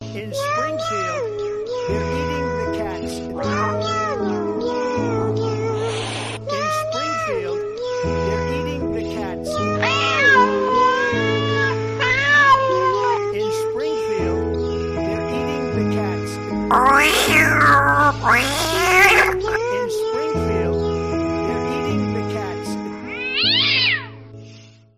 Reactions
They're Eating The Cats Trump With Sad Meow